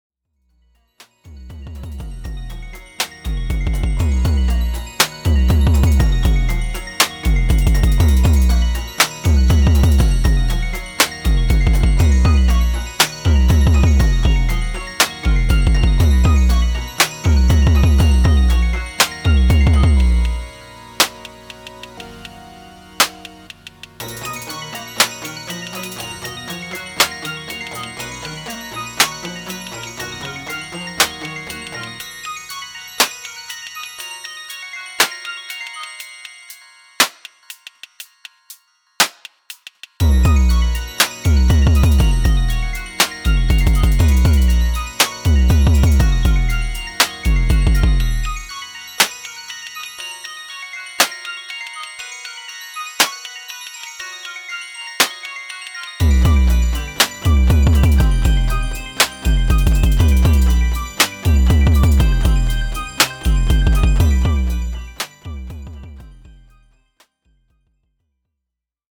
Downtempo
Synthesized chillhop/hip-hop adjacent downtempo instrumental.
downtempo_0.mp3